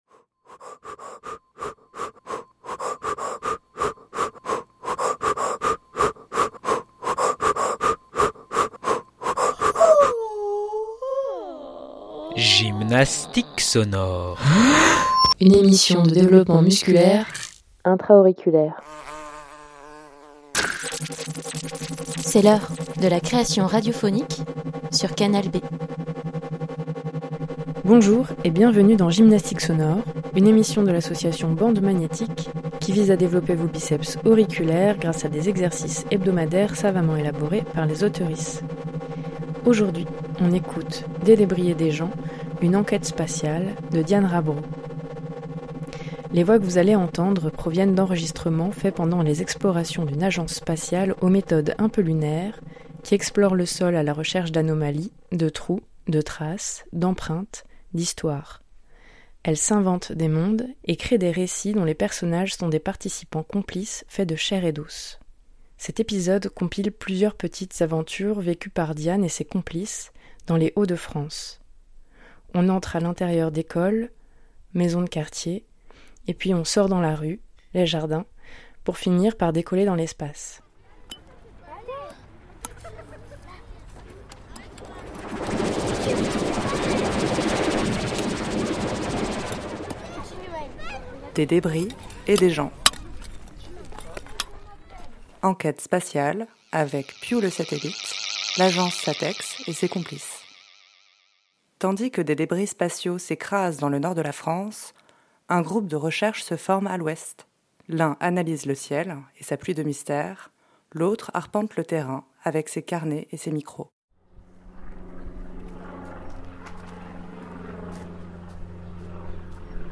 Les voix que vous allez entendre proviennent d'enregistrements faits pendant les expéditions d’une agence spatiale aux méthodes un peu lunaires… qui explore le sol à la recherche d’anomalies, de trous, de traces, d’empreintes, d’histoires.
On entre à l’intérieur d’écoles, maisons de quartier, maisons, jardin, et puis on sort dans la rue pour finir par décoller dans l’espace.